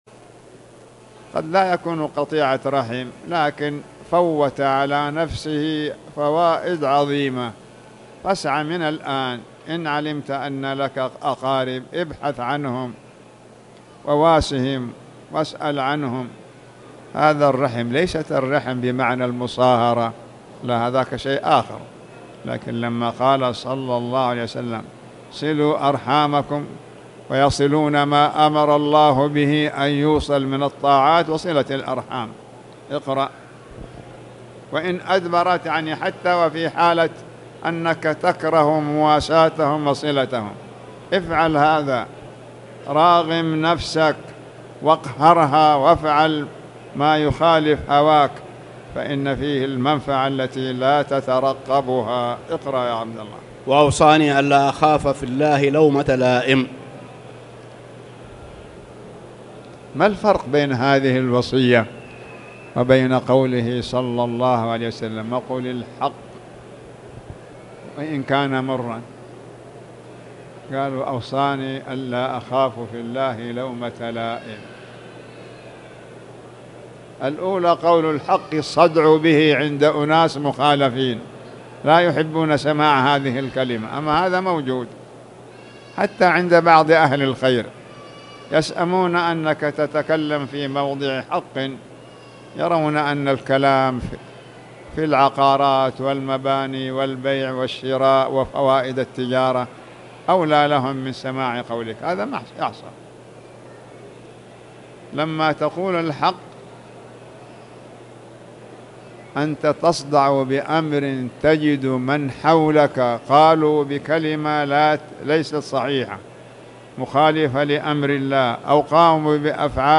تاريخ النشر ٢٩ جمادى الآخرة ١٤٣٨ هـ المكان: المسجد الحرام الشيخ